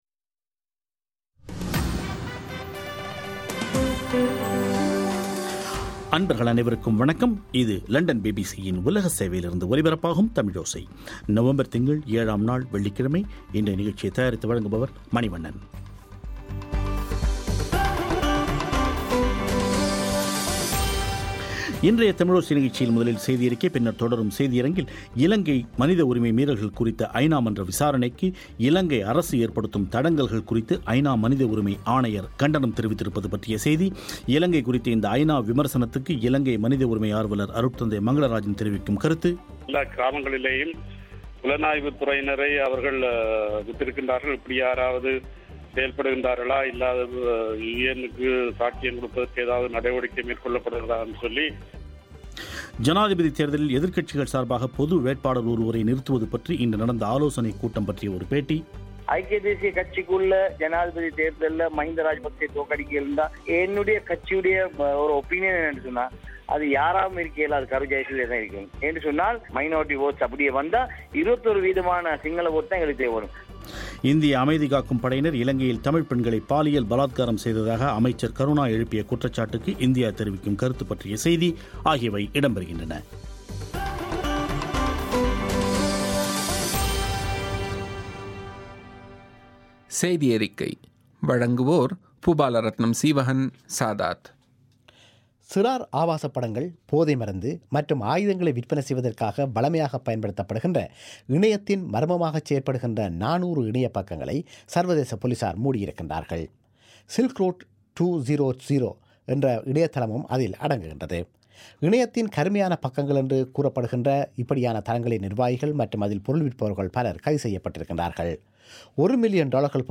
ஜனாதிபதி தேர்தலில் எதிர்க்கட்சிகள் சார்பாக பொது வேட்பாளர் ஒருவரை நிறுத்துவது பற்றி இன்று நடந்த ஆலோசனைக் கூட்டம் பற்றிய ஒரு பேட்டி